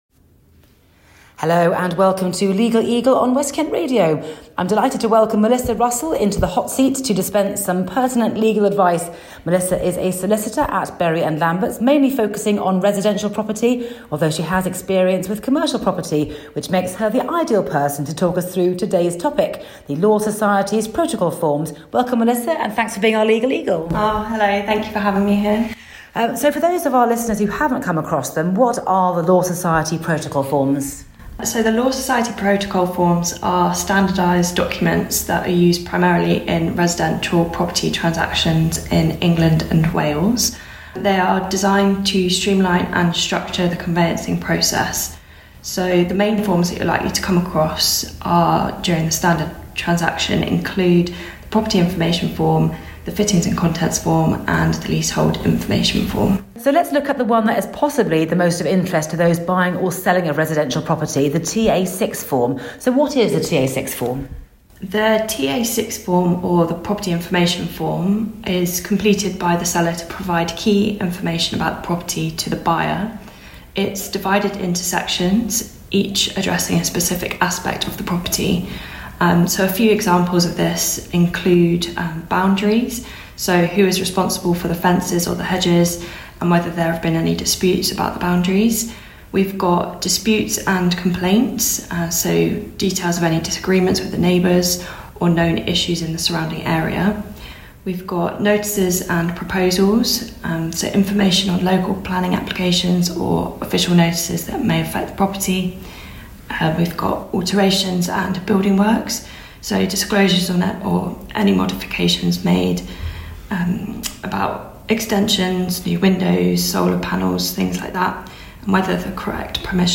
More from INTERVIEW REPLAYS